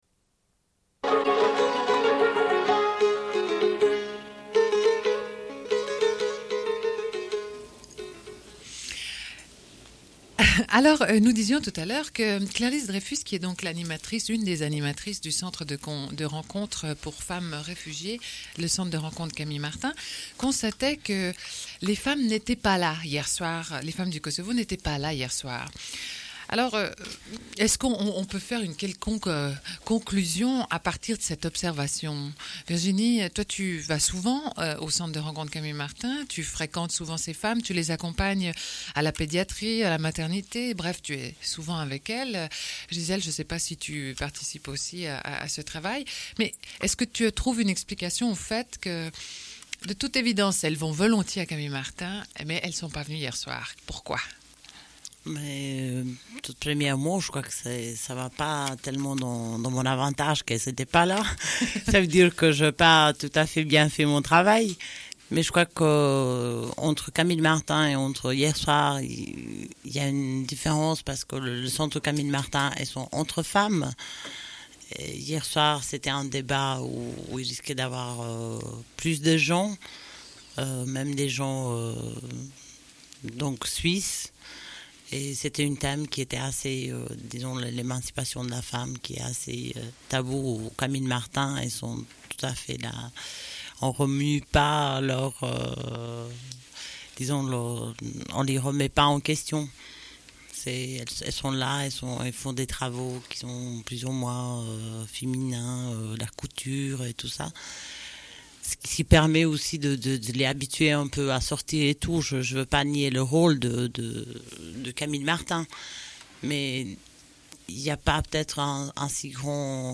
Une cassette audio, face B00:29:11